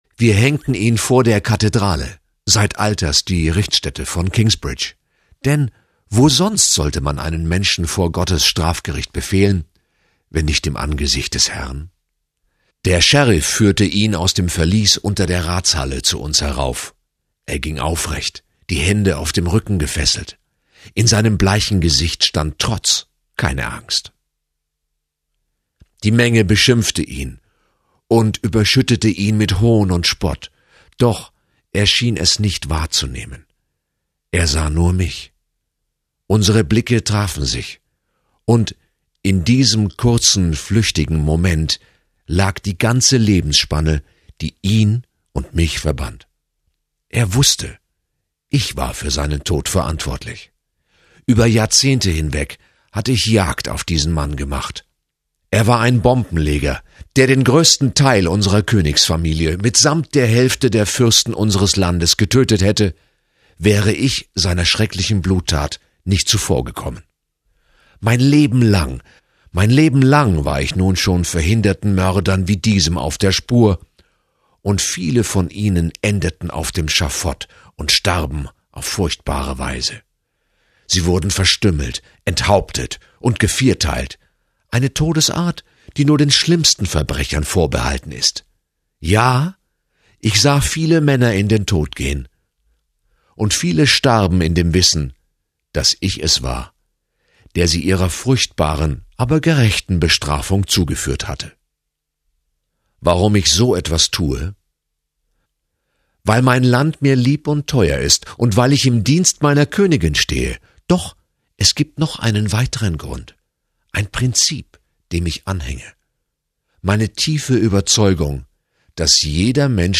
Leseprobe Das Fundament der Ewigkeit